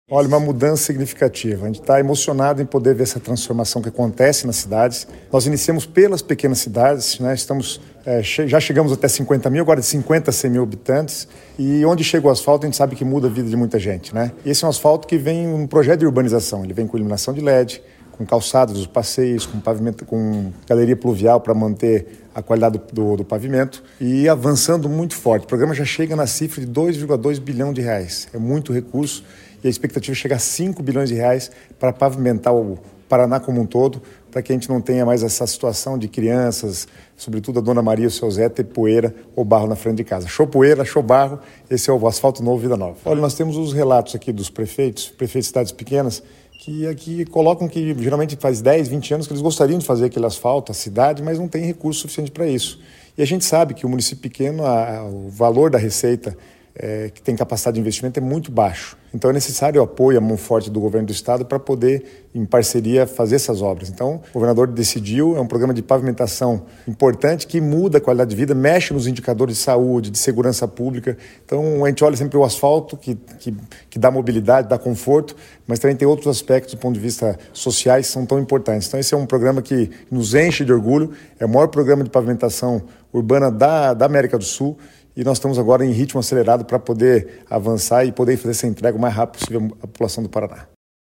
Sonora do secretário das Cidades, Guto Silva, sobre o anúncio do investimento do programa Asfalto Novo, Vida Nova para mais 10 municípios